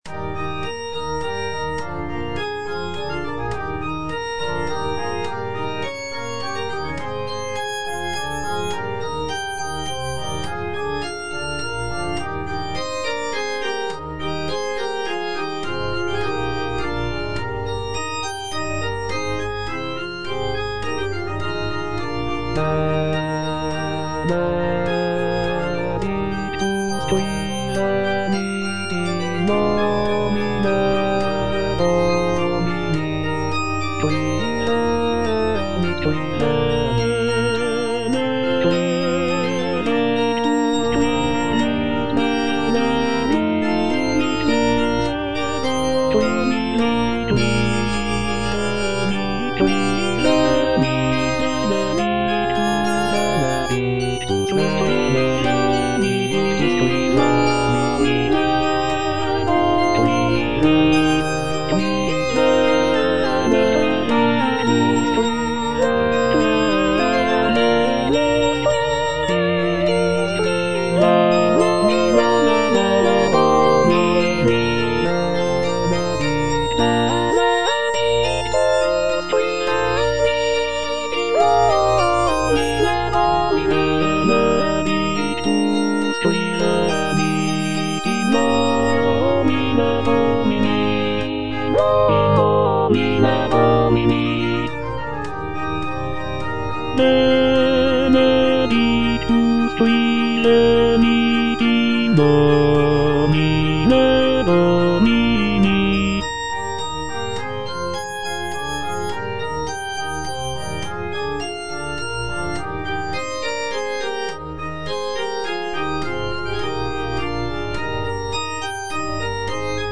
M. HAYDN - REQUIEM IN C (MISSA PRO DEFUNCTO ARCHIEPISCOPO SIGISMUNDO) MH155 Benedictus - Tenor (Voice with metronome) Ads stop: auto-stop
The work is characterized by its somber and mournful tone, reflecting the solemnity of a funeral mass. Featuring beautiful choral harmonies and expressive melodies, Haydn's Requiem in C showcases his mastery of sacred music and ability to evoke deep emotional responses through his compositions.